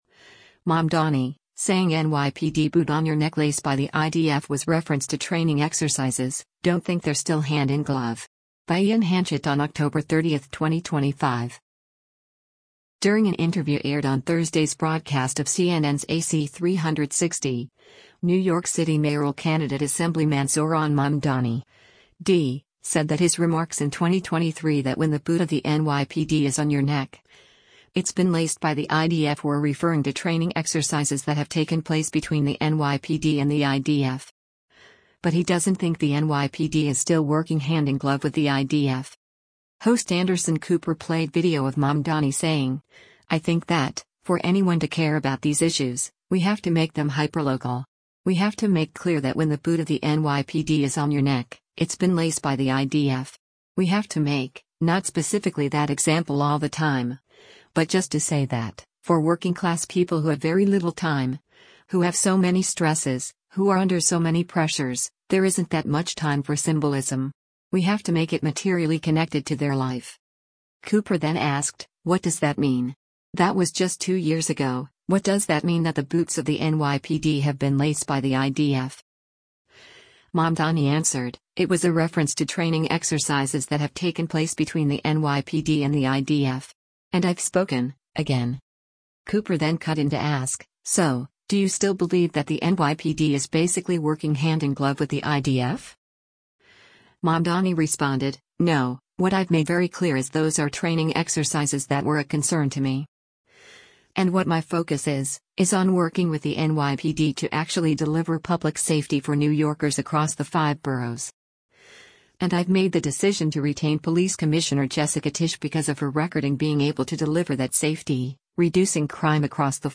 During an interview aired on Thursday’s broadcast of CNN’s “AC360,” New York City mayoral candidate Assemblyman Zohran Mamdani (D) said that his remarks in 2023 that “when the boot of the NYPD is on your neck, it’s been laced by the IDF” were referring to “training exercises that have taken place between the NYPD and the IDF.” But he doesn’t think the NYPD is still working hand in glove with the IDF.